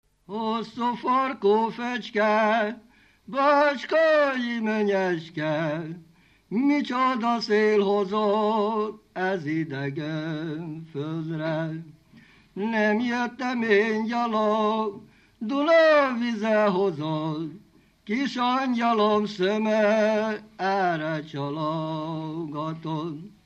Dunántúl - Pozsega vm. - Imszovác
Stílus: 1.2. Ereszkedő pásztordalok
Szótagszám: 6.6.6.6
Kadencia: 4 (1) 4 1